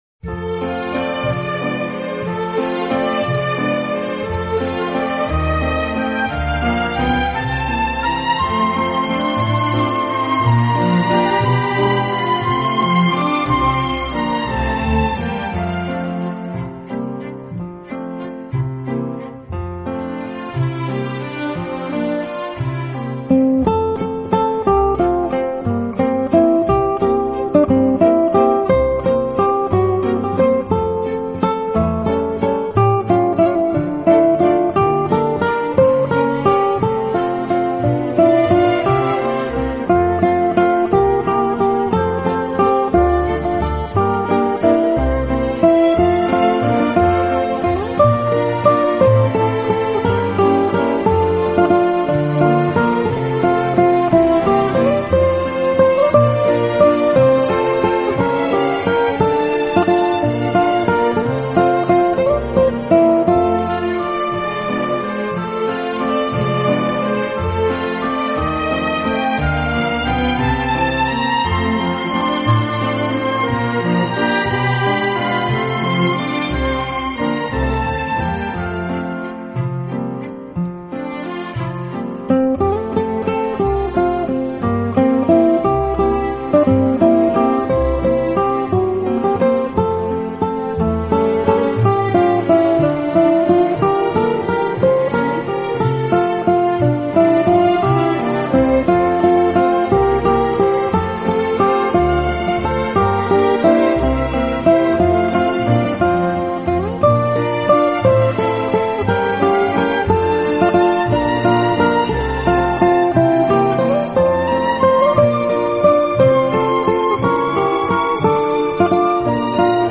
资源类型：Acoustic Guitar/Easy Listening